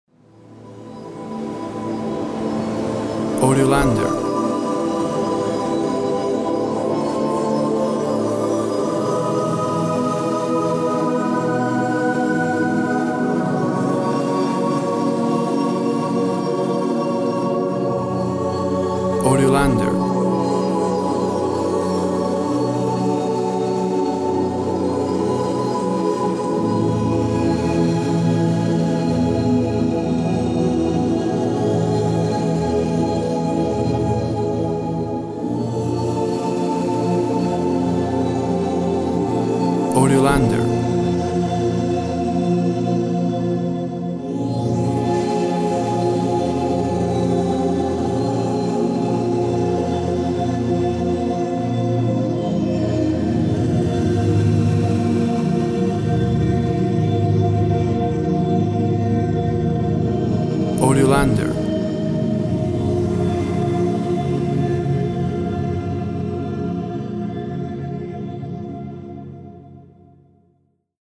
Lush and dreamy choir/synth sounds.
Tempo (BPM) 56